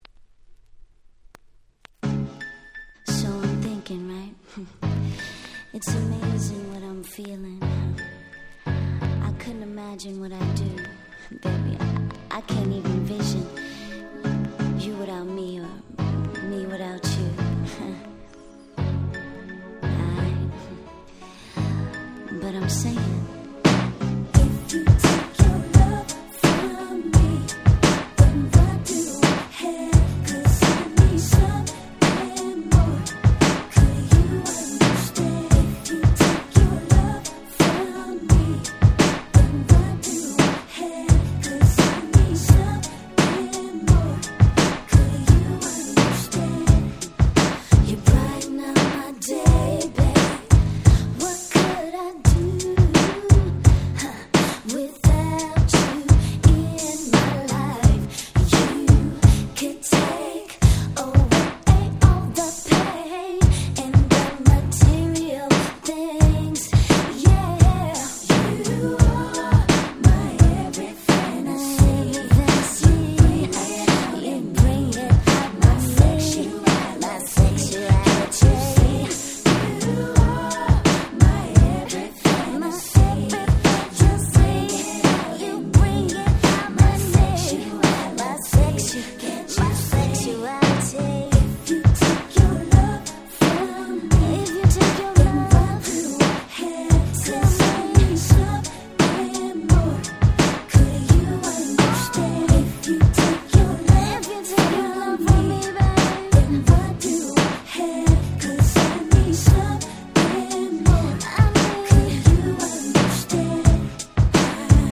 96' Nice R&B / Hip Hop Soul !!
いぶし銀ながら雰囲気のあるナイスなHip Hop Soul !!